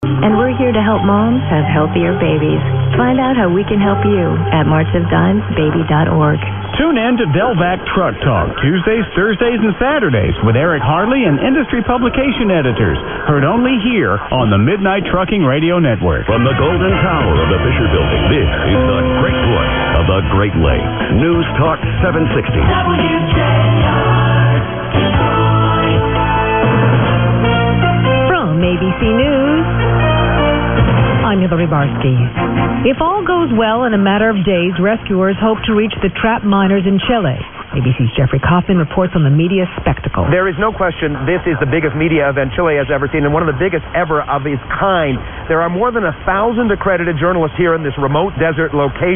I had some booming signals here this morning from some of the common d=stations.
Anyway here are some recordings from today here on the West of Scotland.
101011_0700_680_crtr_booming.mp3